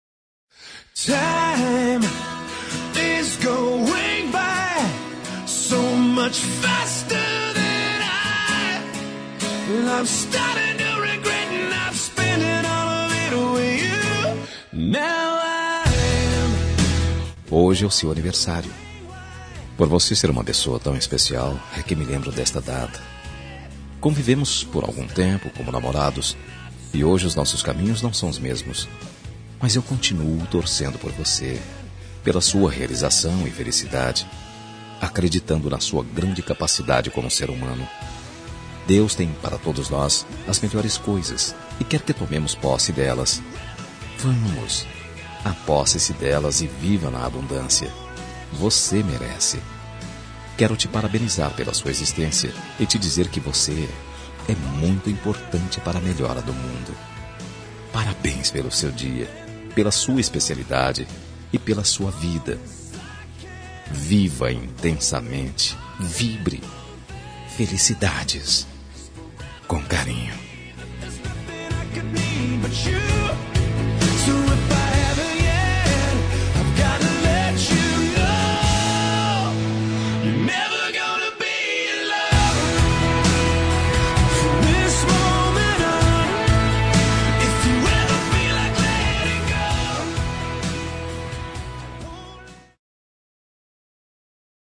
Telemensagem de Aniversário de Ex. – Voz Masculina – Cód: 1372